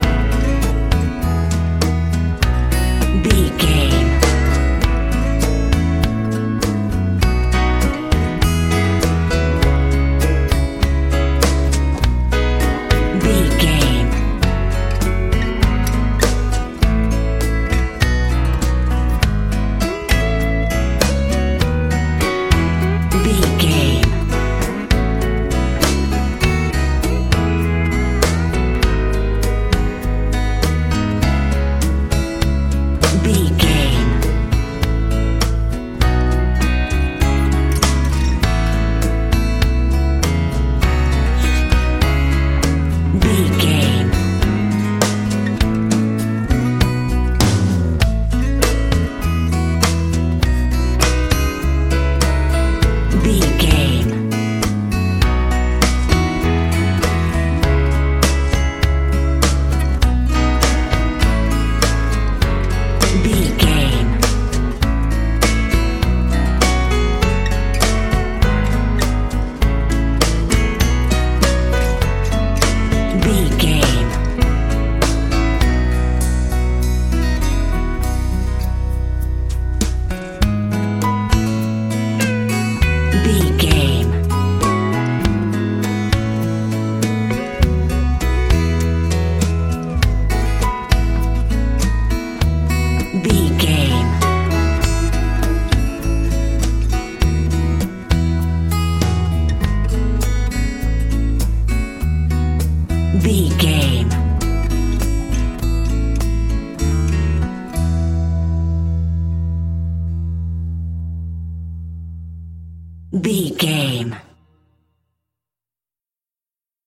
Ionian/Major
drums
acoustic guitar
piano
violin
electric guitar